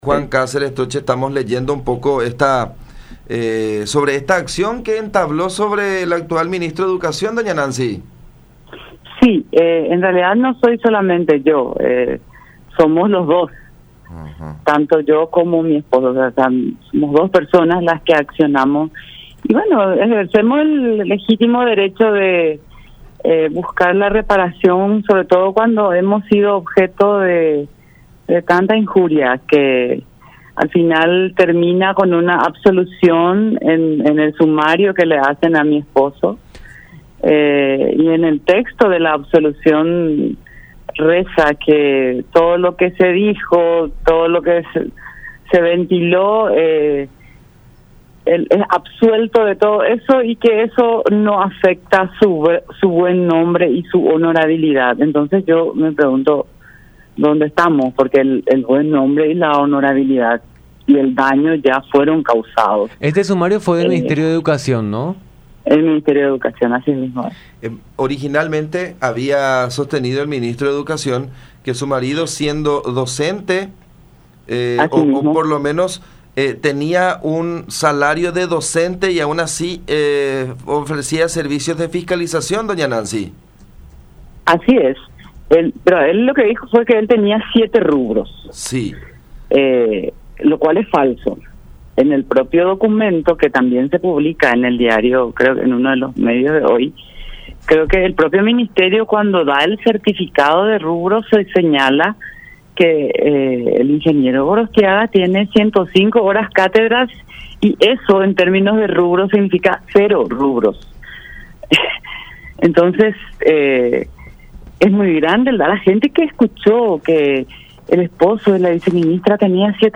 expresó Ovelar en comunicación con La Unión